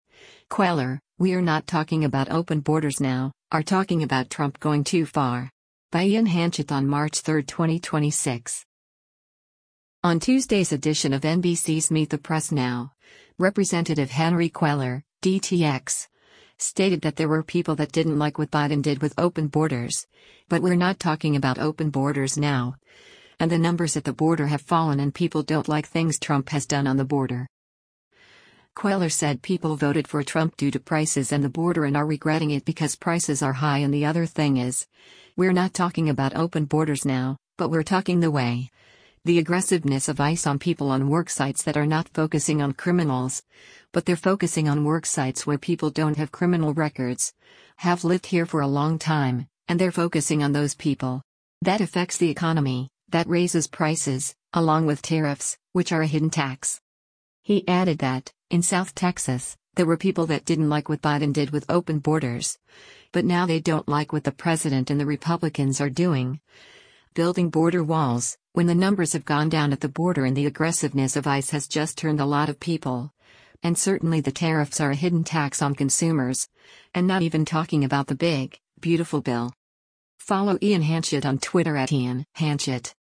On Tuesday’s edition of NBC’s “Meet the Press NOW,” Rep. Henry Cuellar (D-TX) stated that “There were people that didn’t like what Biden did with open borders,” but “we’re not talking about open borders now,” and the numbers at the border have fallen and people don’t like things Trump has done on the border.